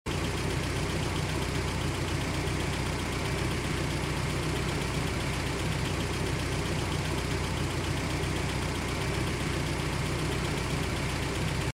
Звуки двигателя автомобиля